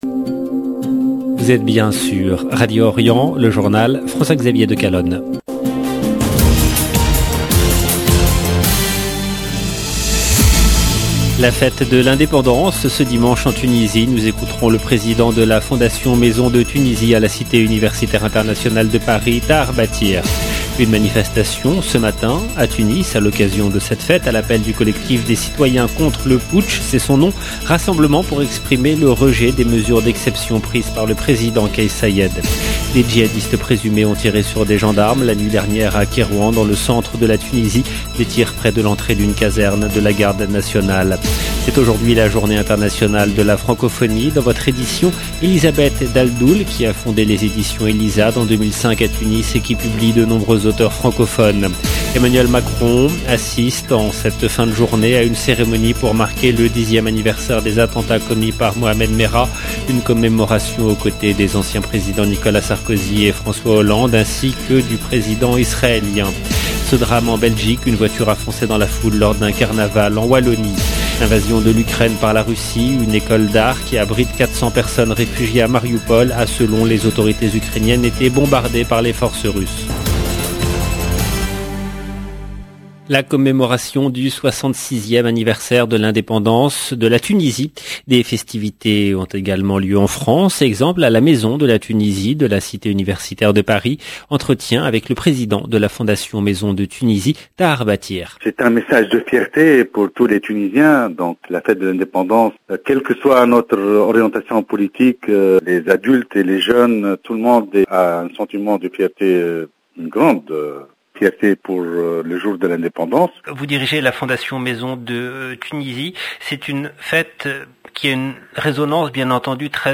EDITION DU JOURNAL DU SOIR EN LANGUE FRANCAISE DU 20/3/2022